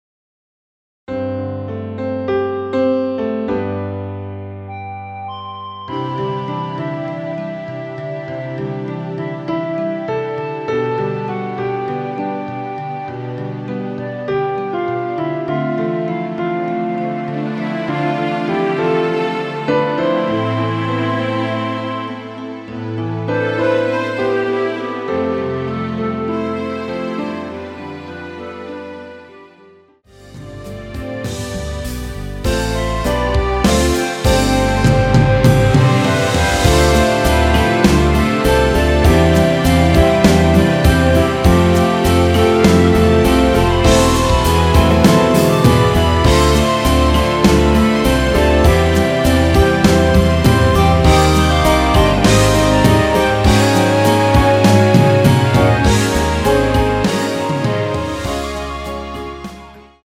전주 없이 시작 하는 곡이라 전주 만들어 놓았습니다.(미리듣기참조)
원키 멜로디 포함된 MR입니다.
앞부분30초, 뒷부분30초씩 편집해서 올려 드리고 있습니다.
(멜로디 MR)은 가이드 멜로디가 포함된 MR 입니다.